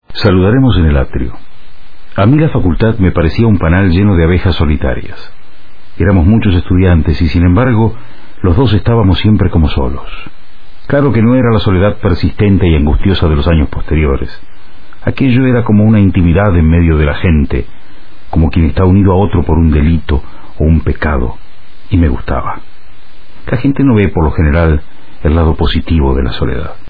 Para escuchar la voz del autor pulsar en la foto